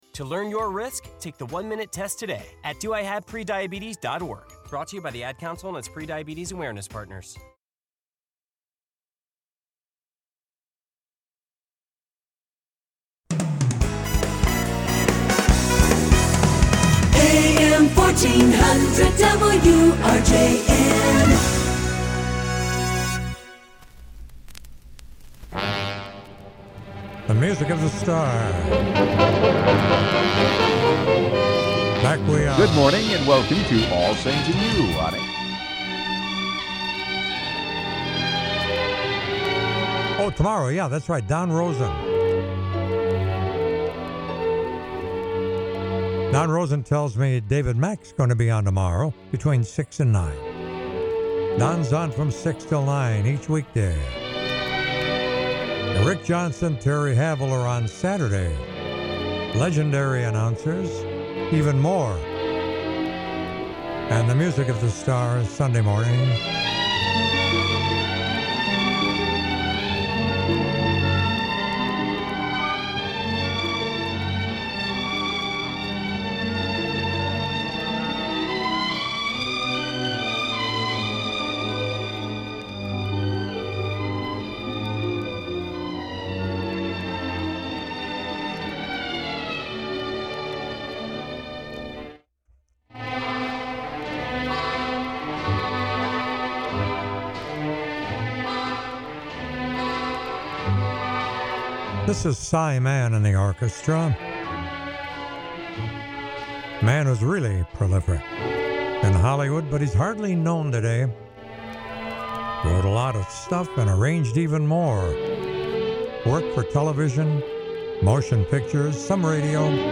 Broadcasts live 7 a.m. to noon Sunday mornings across Wisconsin.